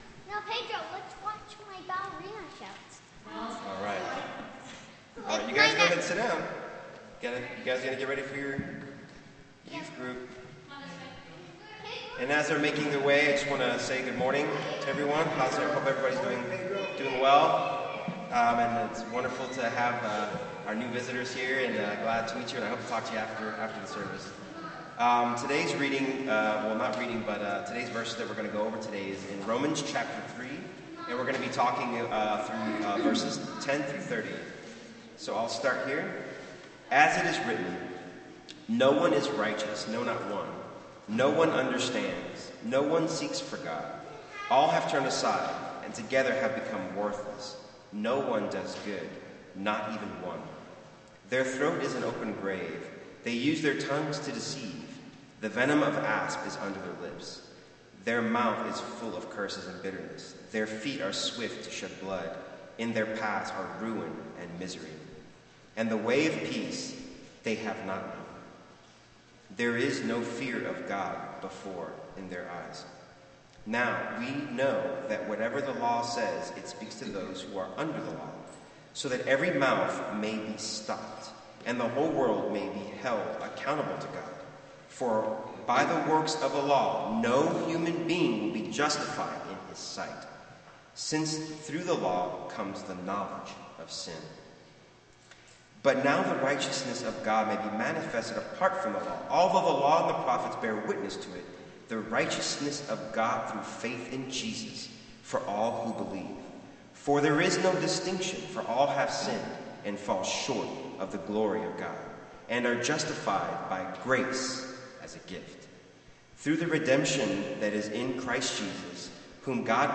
Sermon Archives | Aspen Ridge Church